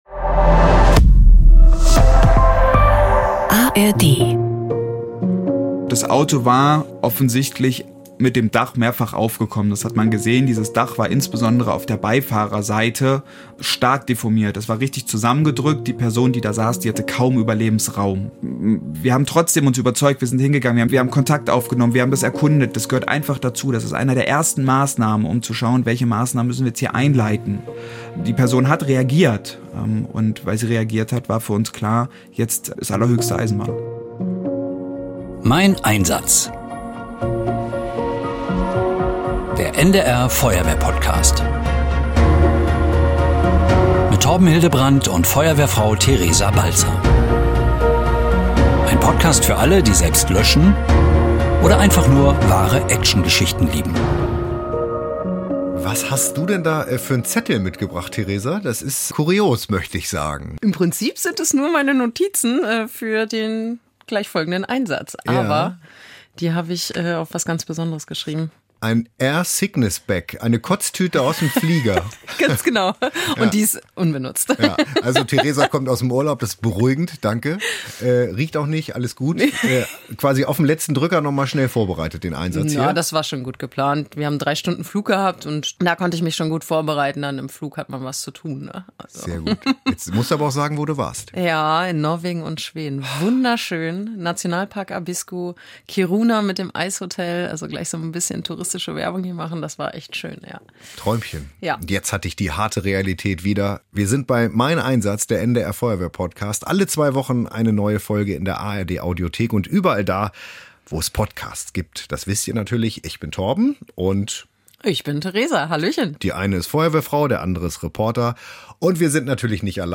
Emotionen pur und spannende Geschichten.